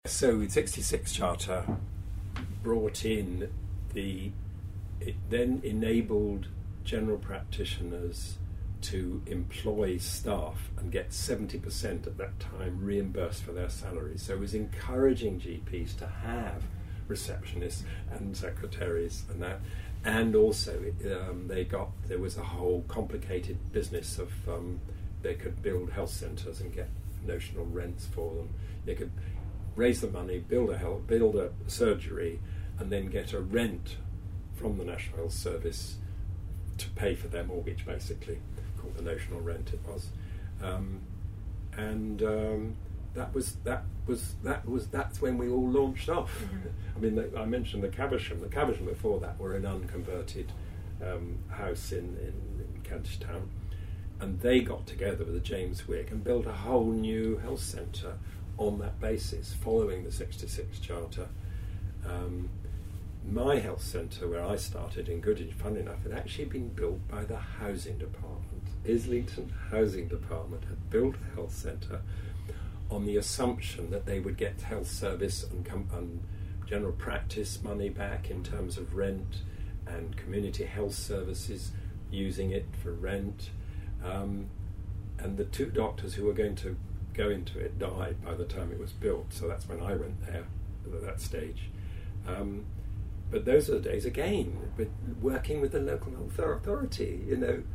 He was interviewed by the College for NHS70 in February 2018.